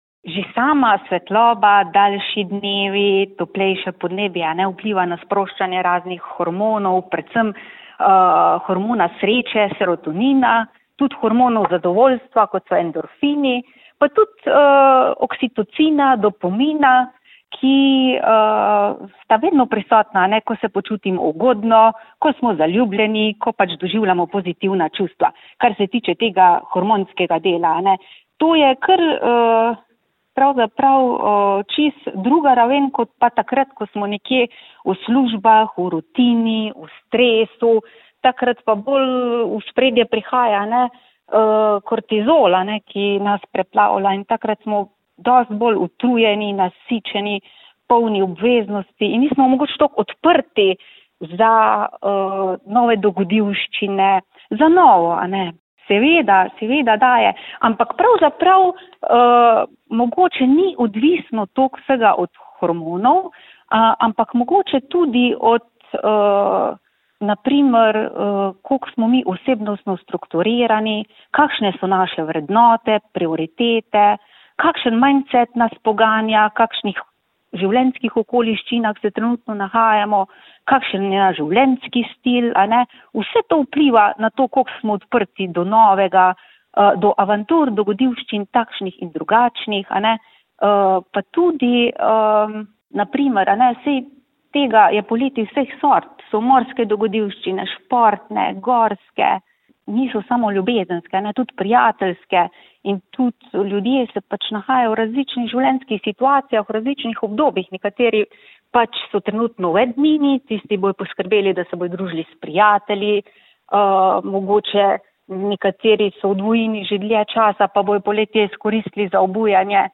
V pogovoru z dr. psihologije